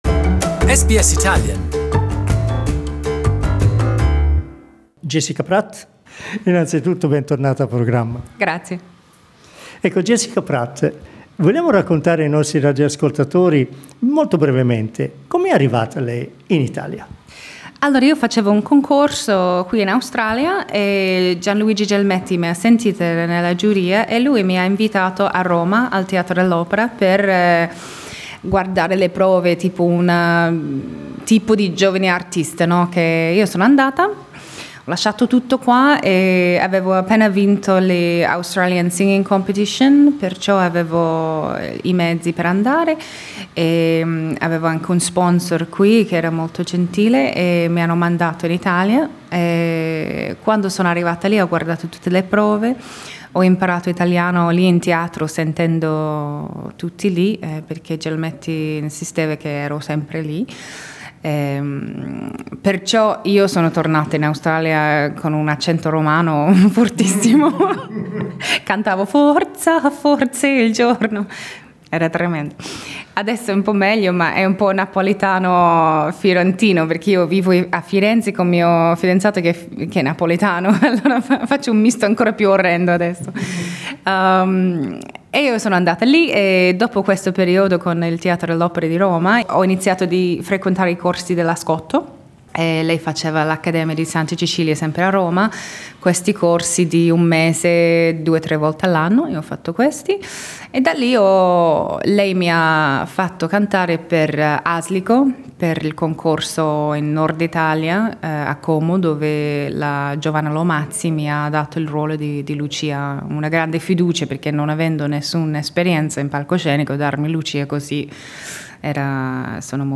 In questa intervista la cantante ci svela i suoi hobby, i suoi amori e molto di sè stessa.